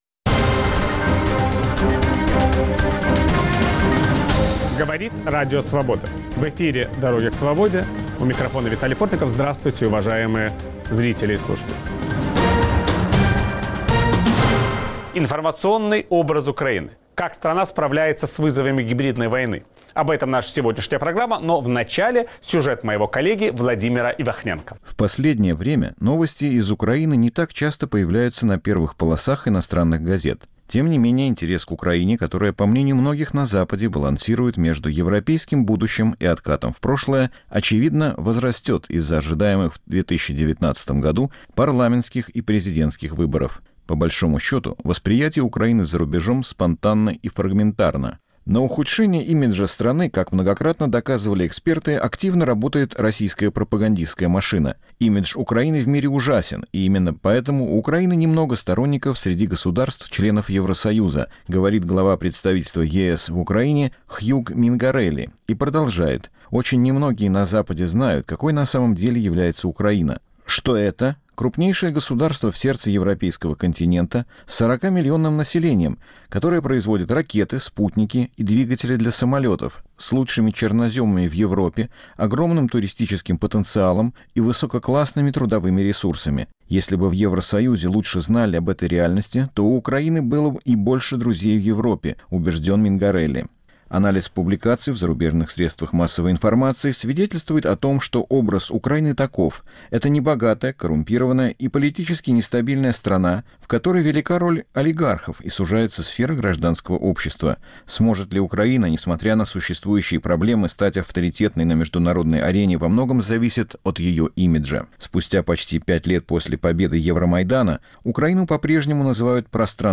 Как работать с населением Донбасса и Крыма? Собеседник Виталия Портникова - бывший заместитель министра информационной политики Украины Татьяна Попова.